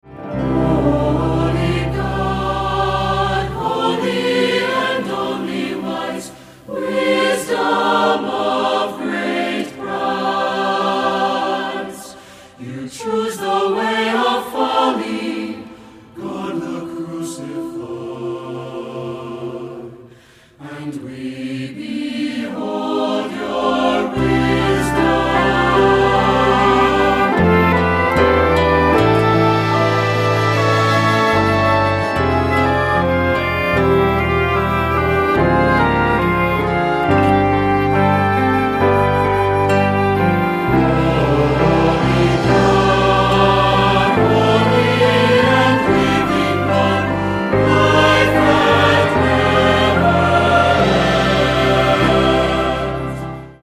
Accompaniment:      Keyboard, Flute;Oboe
Music Category:      Christian
For cantor or soloist.